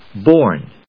/bˈɔɚn(米国英語), bˈɔːn(英国英語)/